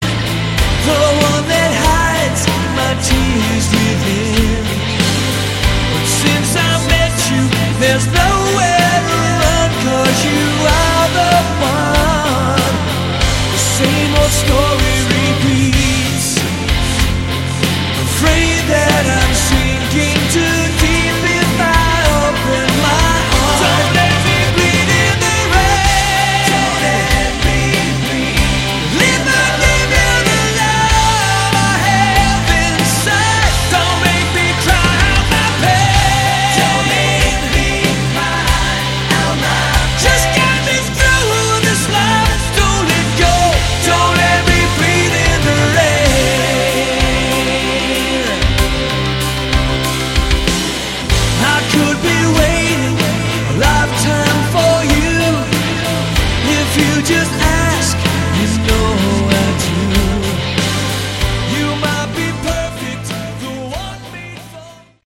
Category: Melodic Rock
rhythm & lead guitars, bass
keyboards
Semi ballad